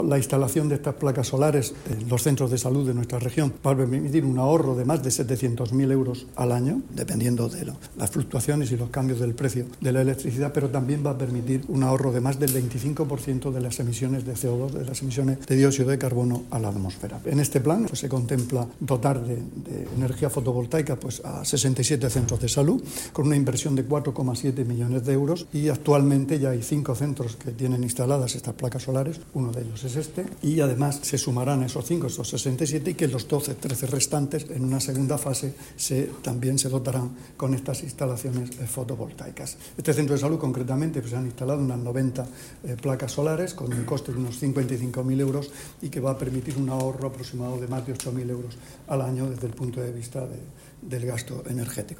Sonido/ Declaraciones del consejero de Salud, Juan José Pedreño, sobre las placas solares instaladas en centros de salud.
El consejero de Salud, Juan José Pedreño, visitó hoy la instalación de las placas solares ubicadas en el centro de salud de El Carmen de Murcia.